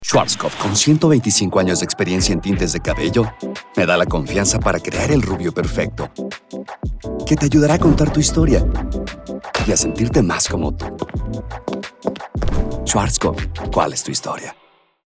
SCHWARZKOPF_TVAD_SpanishNeutral
Neutral Spanish / Mexican / Latin American English /